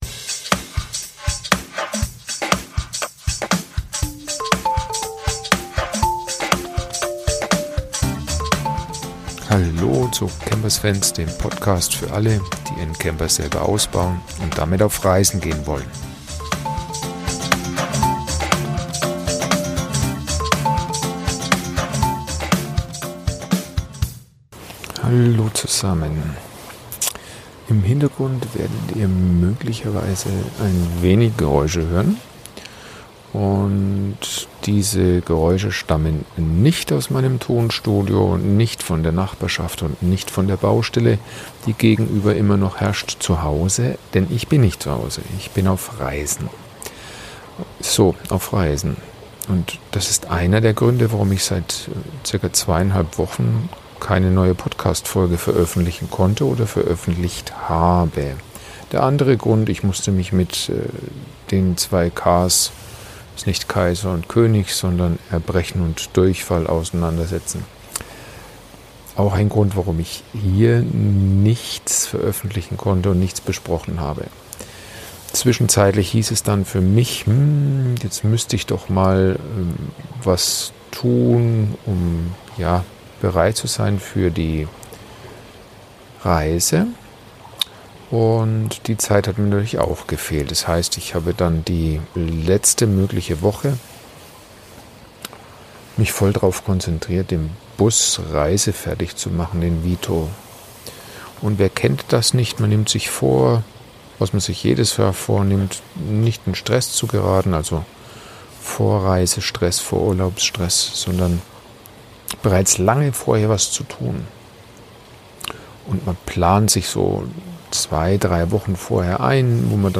Deshalb jetzt ein Podcast, aus der Nacht, in wunderschöner Umgebung und unterm Sternenzelt, nah am Wasser, um Euch ein...